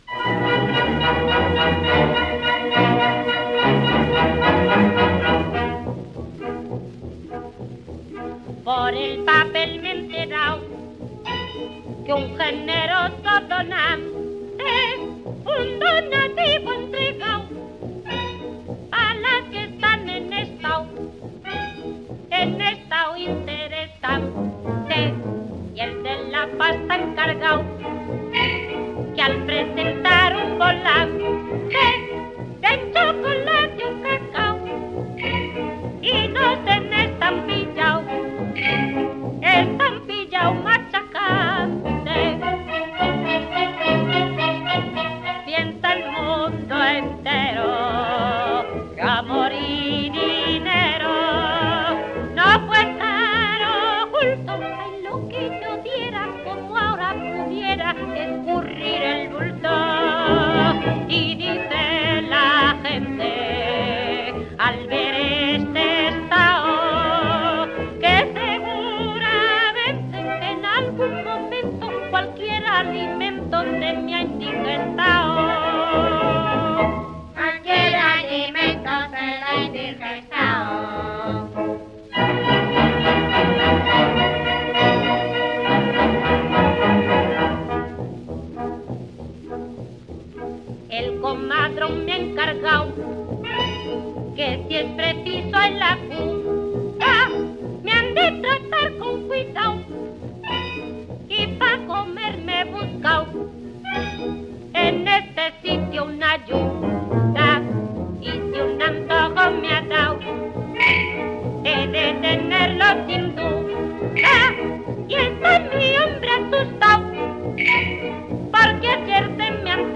coro
78 rpm